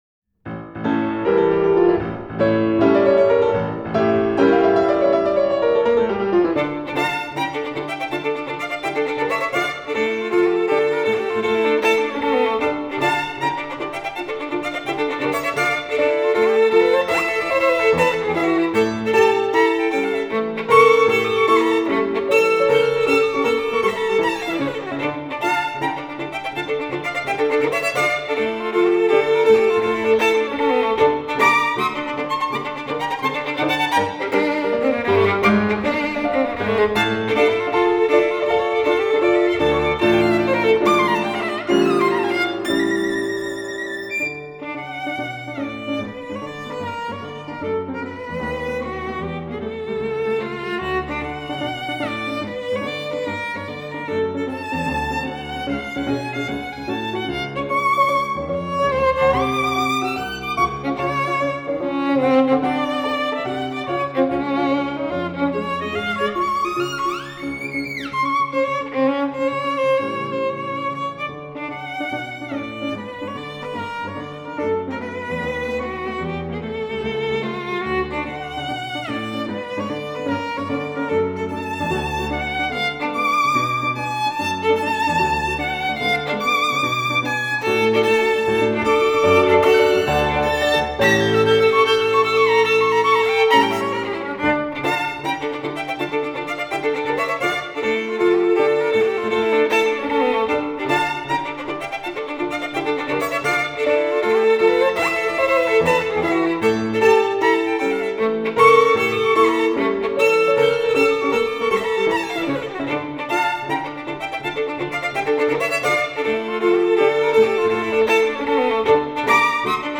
ferdinand_laub_-_saltarello_op_4_no_4.mp3